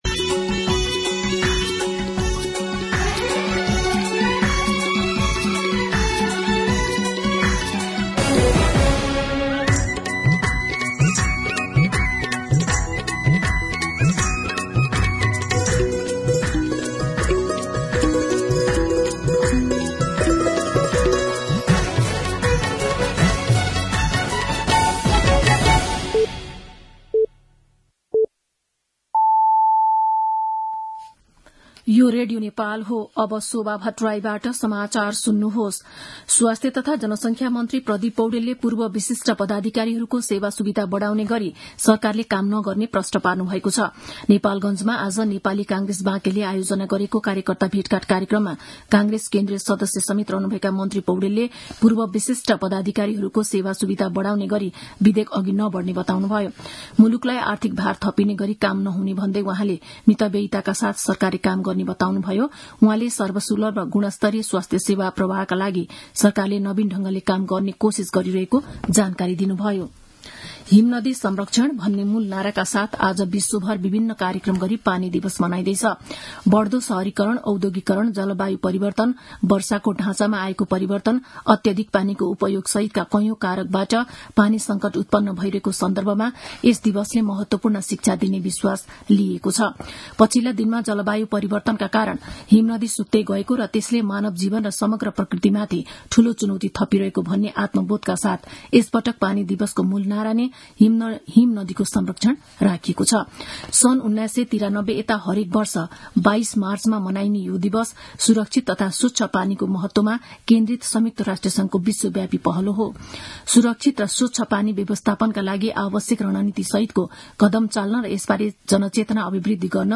मध्यान्ह १२ बजेको नेपाली समाचार : ९ चैत , २०८१
12pm-News-12-9.mp3